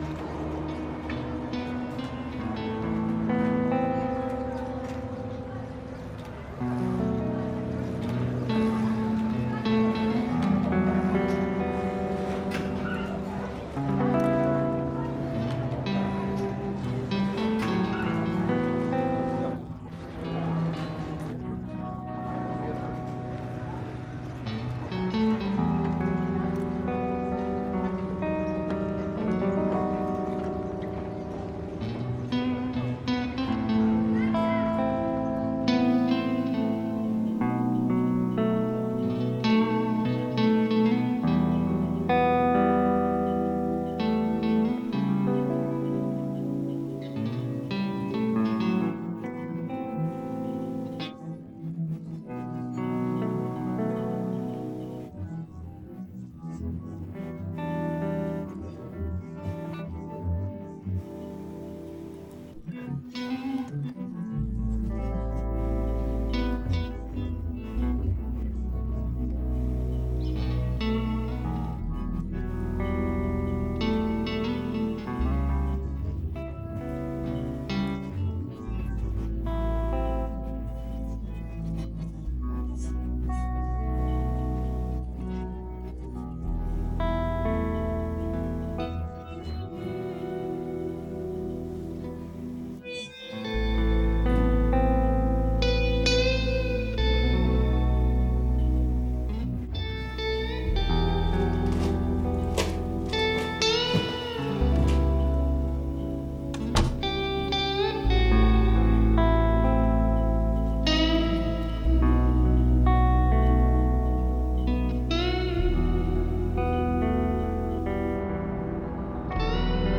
Попытка убрать речь